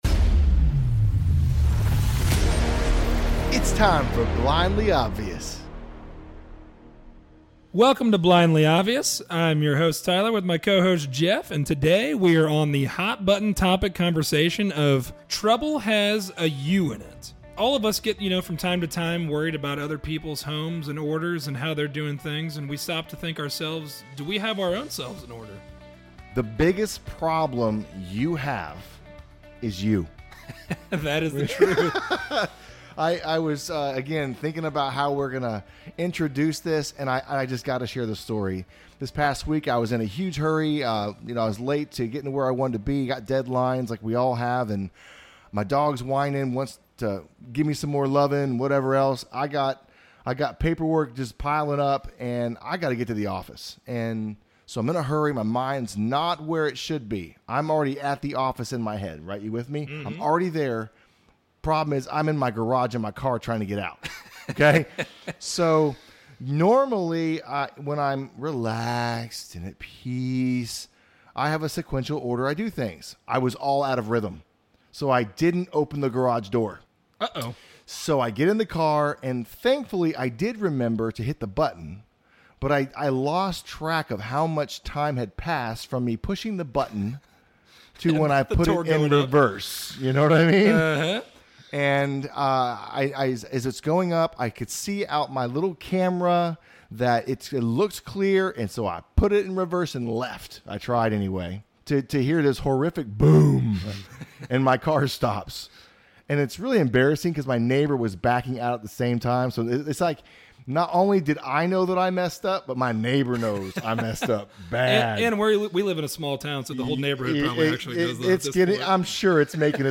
A conversation about how usually the trouble we find ourselves in all leads back to the U that’s in it.